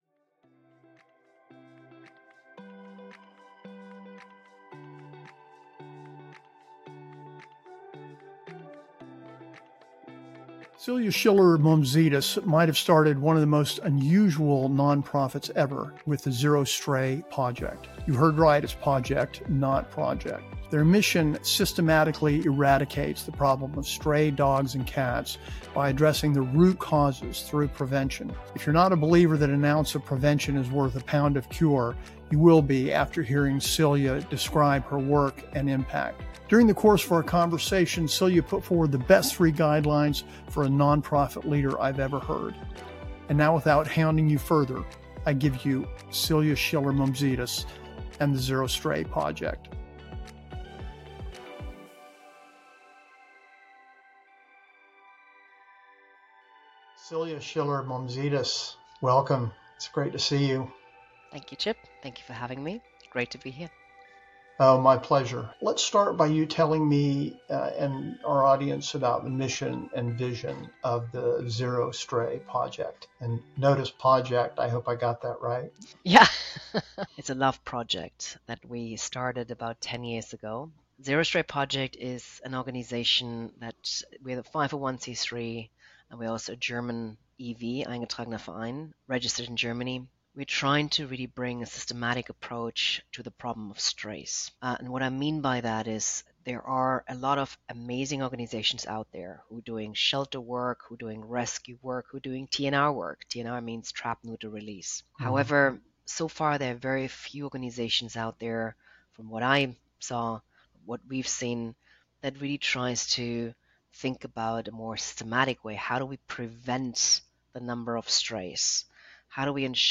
Interview Transcript and Article Link Episode 2